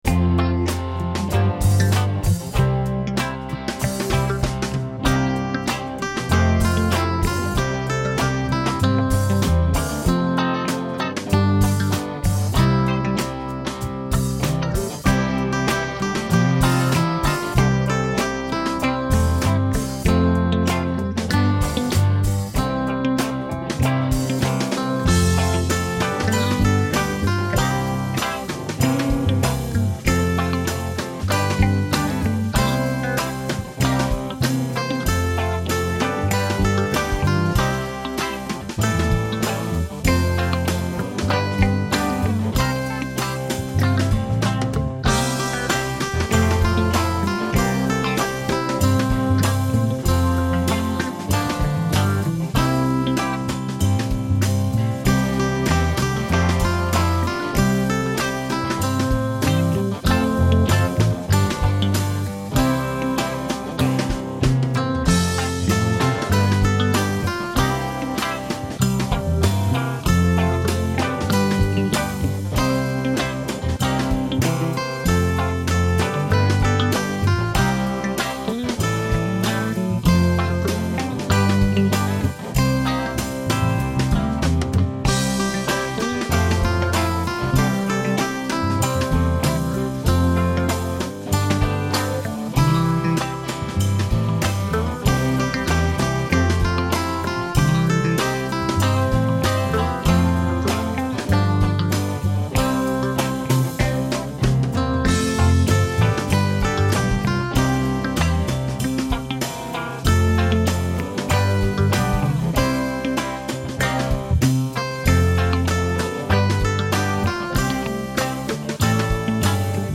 uplifting hymn